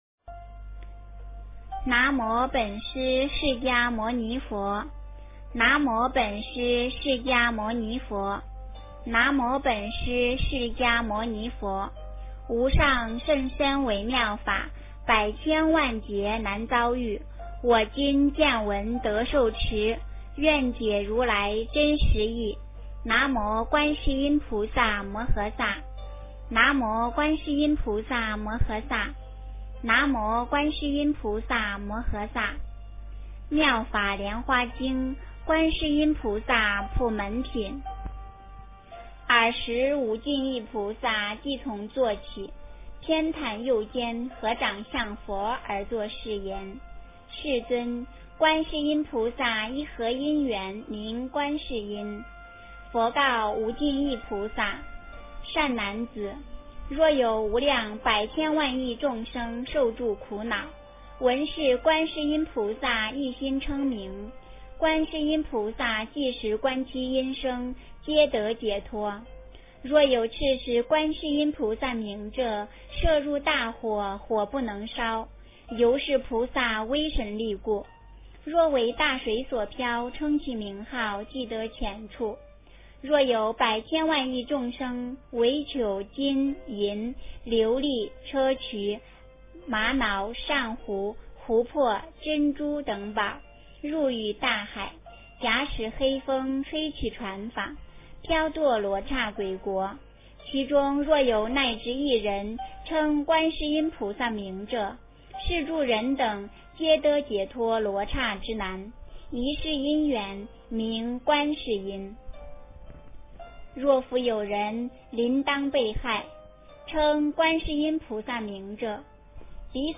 观世音菩萨普门品 - 诵经 - 云佛论坛